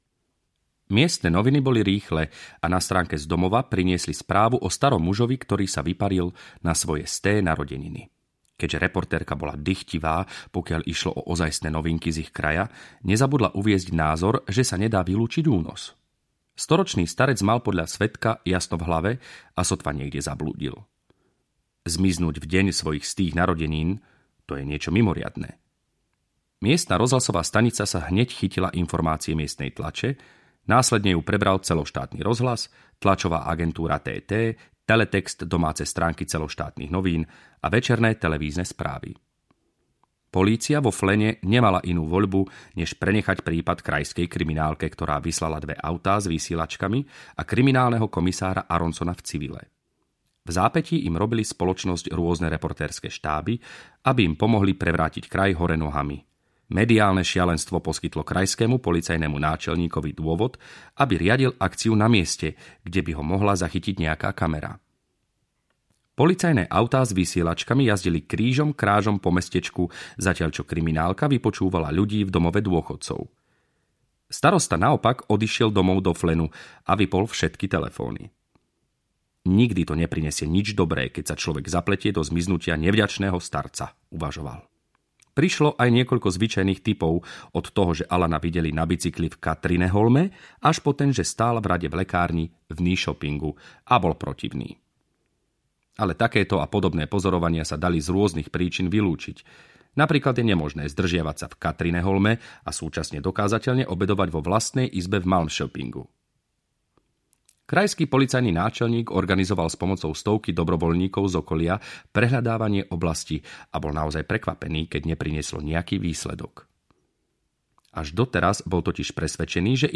Ukázka z knihy
V slovenčine knihu vydalo vydavateľstvo Ikar (2013), audioknihu načítal Ľuboš Kostelný.